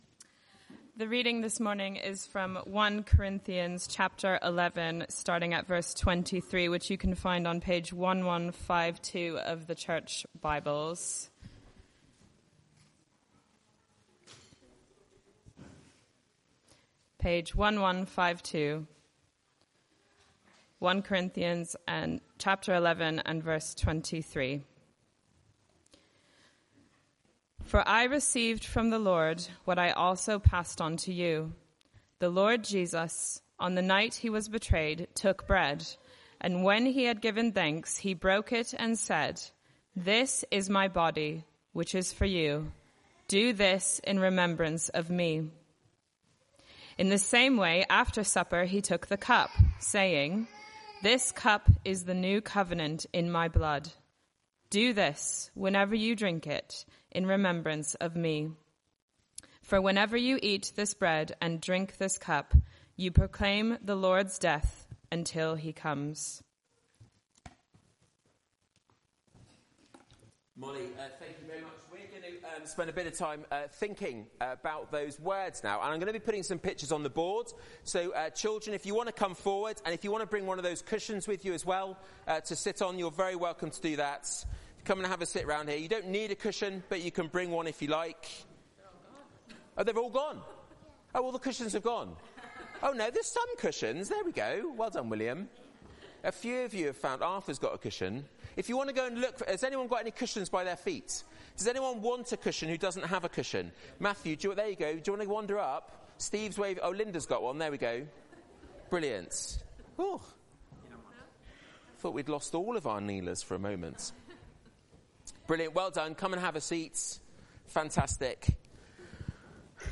Media for Arborfield Morning Service on Sun 23rd Jun 2024 10:00
Theme: Sermon